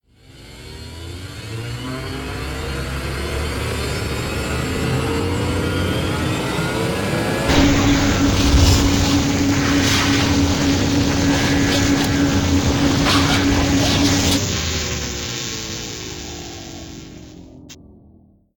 HIDTurret.ogg